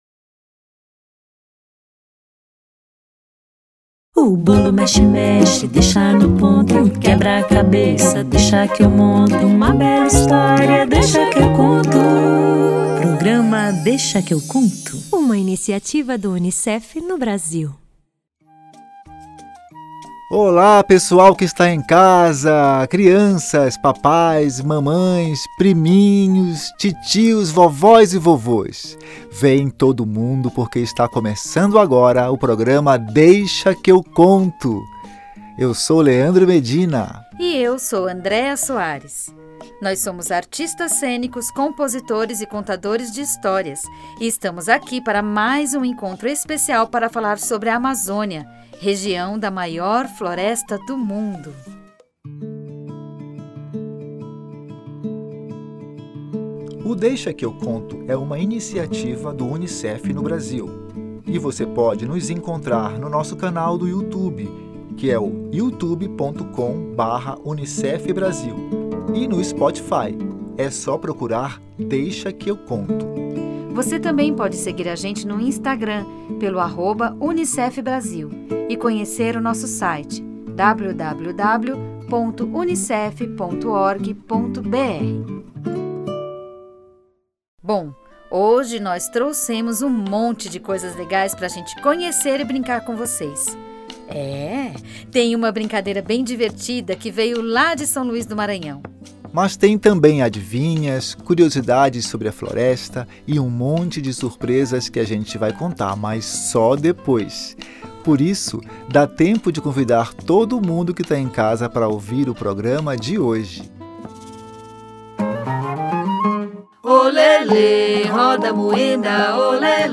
Também vão descobrir curiosidades sobre a língua tupi, a língua indígena mais falada no Brasil, ouvir os sons da Amazônia, brincar de adivinha e de cacuriá.